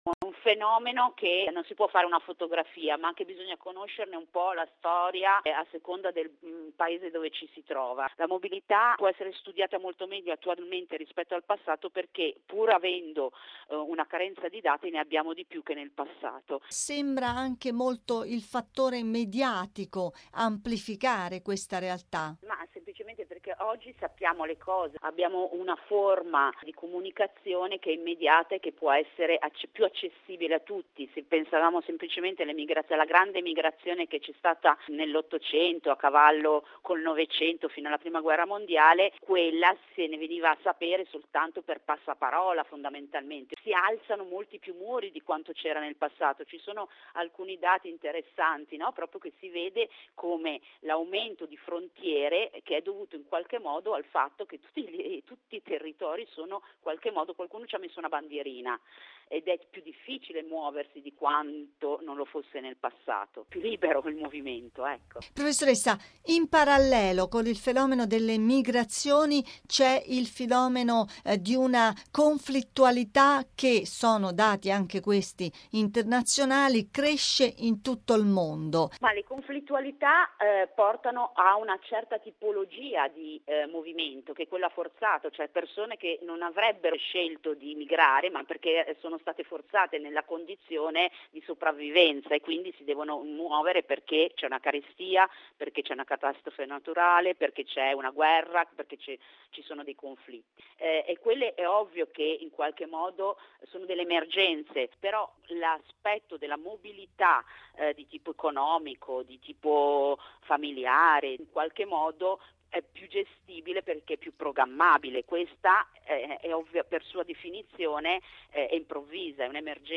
In ogni caso, gli esperti raccomandano uno studio serio e storicizzato del fenomeno. Intervista